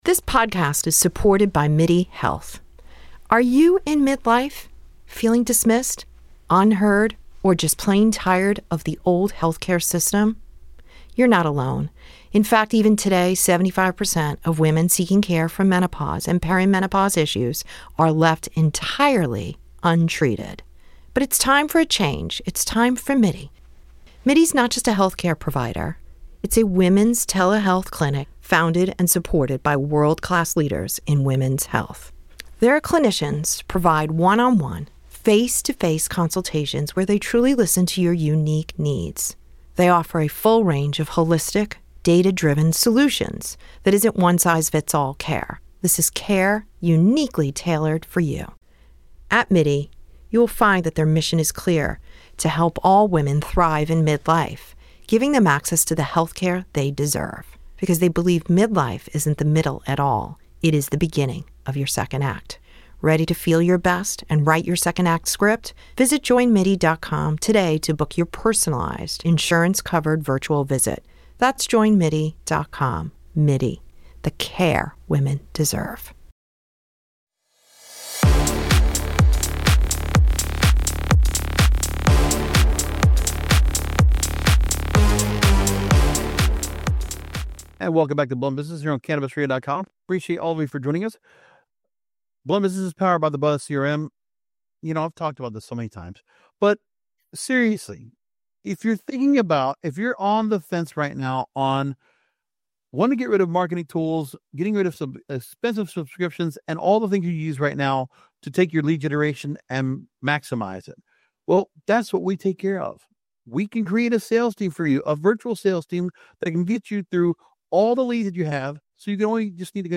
sat down for an insightful interview exploring the brand's journey.